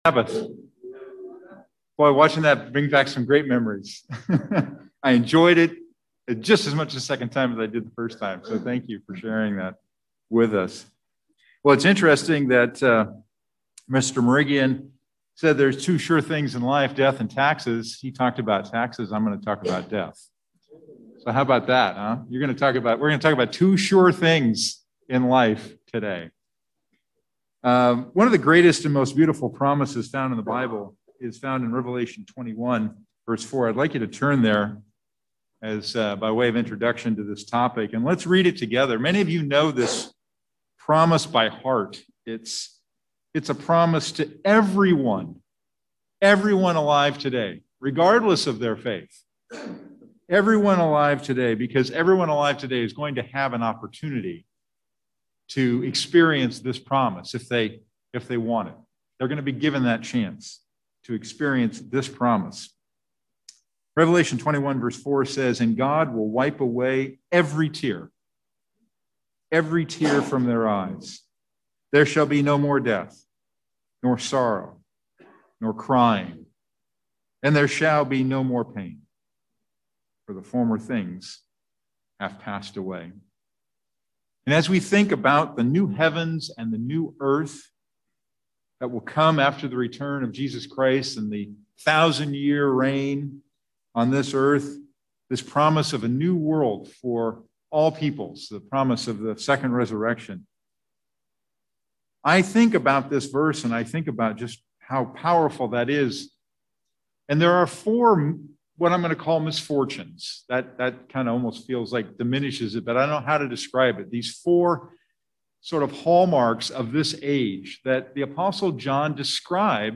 Just as our hope informs our life, it must inform our death, and being prepared mentally, physically, and spiritually is not something we should ignore. In this sermon